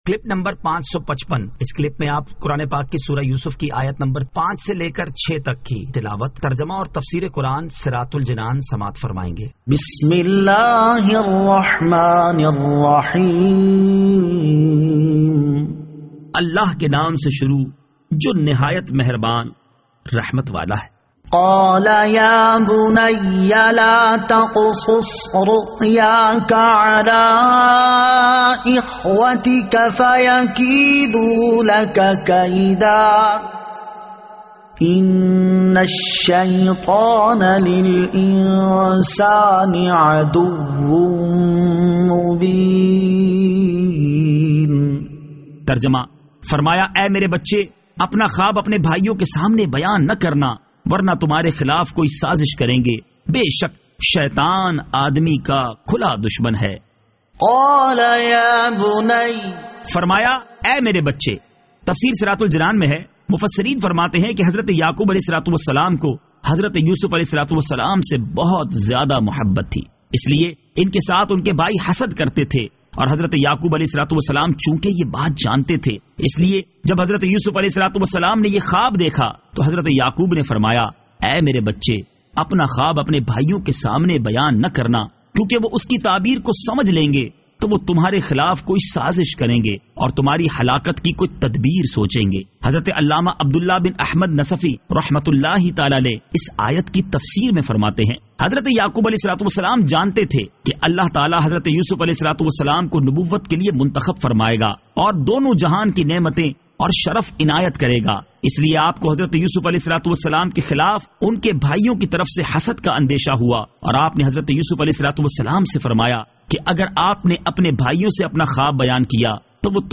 Surah Yusuf Ayat 05 To 06 Tilawat , Tarjama , Tafseer